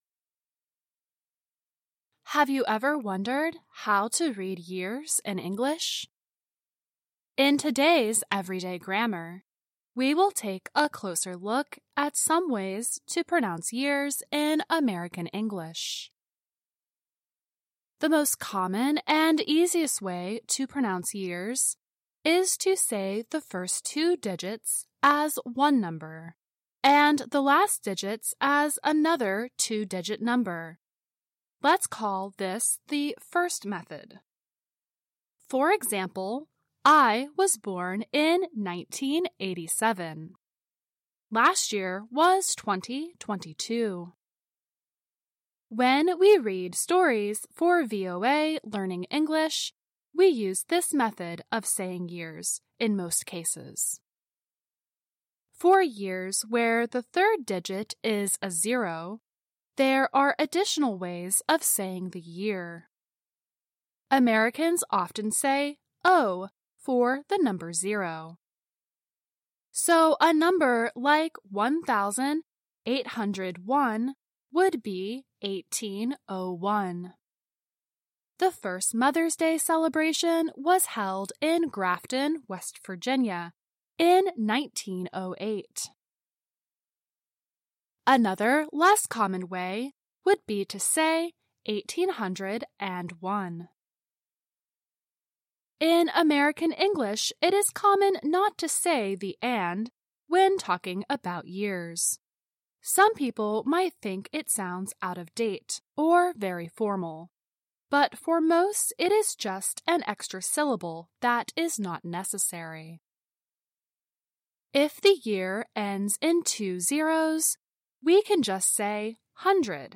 Pronouncing Years in American English